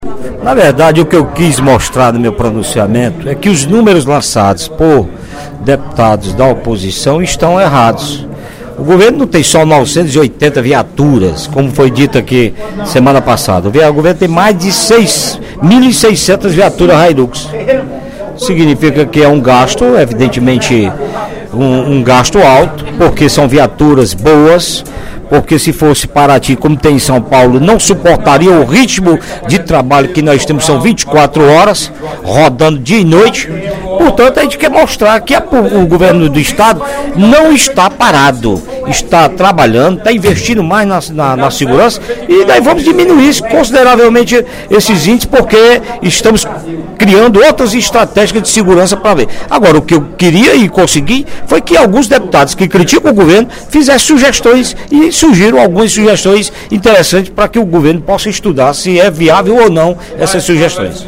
No primeiro expediente da sessão plenária desta quinta-feira (14/03), o deputado Augustinho Moreira (PV) apresentou dados da Secretaria de Segurança Pública e Defesa Social e destacou os investimentos do Governo do Estado no setor.